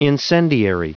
Prononciation du mot incendiary en anglais (fichier audio)
Prononciation du mot : incendiary
incendiary.wav